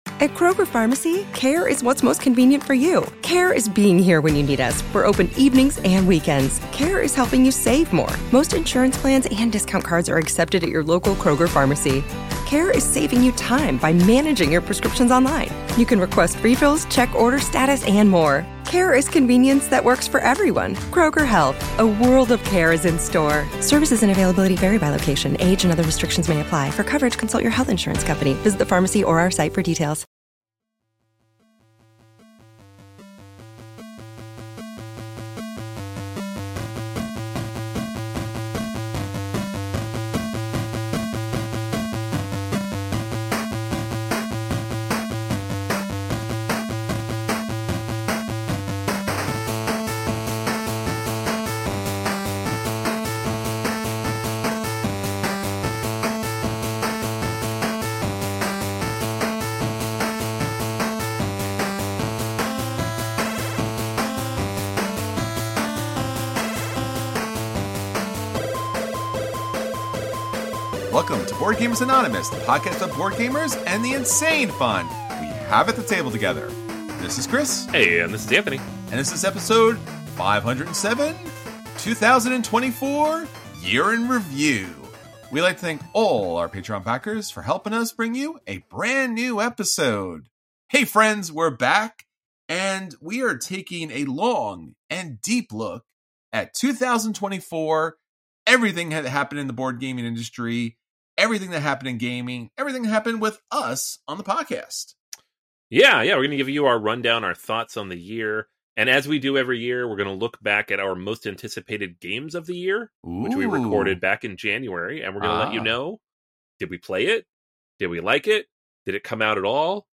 1 Topic: 12 Steps to Joyful Living 1:16:20 Play Pause 16h ago 1:16:20 Play Pause Na później Na później Listy Polub Polubione 1:16:20 Multiple speakers at the 38th. Annual Michigan Convention in 1990 speaking on the topic of - 12 Steps to Joyful Living